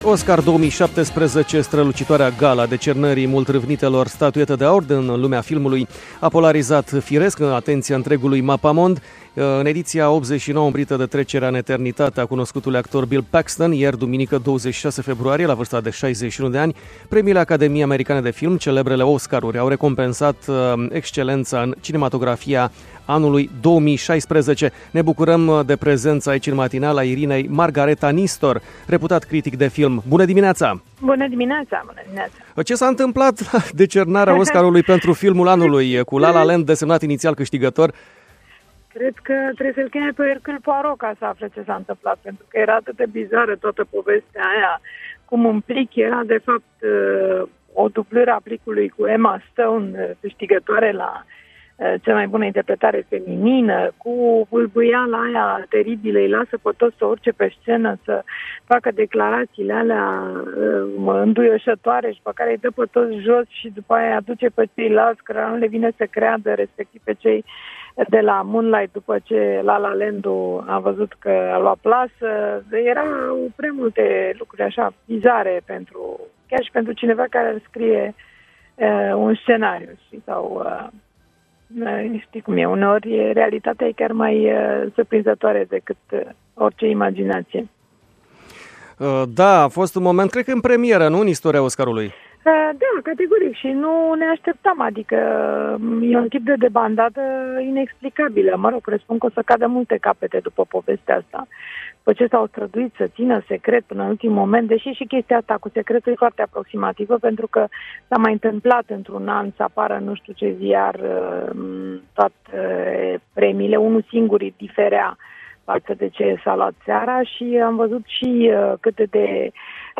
Interviu integral: